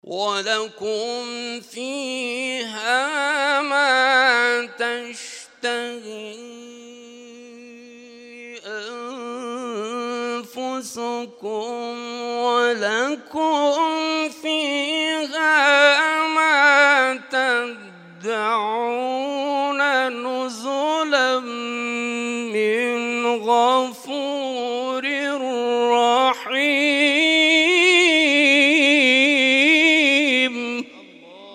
تلاوت‌های محفل هفتگی انس با قرآن آستان عبدالعظیم(ع) + دانلود
محفل هفتگی انس با قرآن در آستان عبدالعظیم(ع) + صوت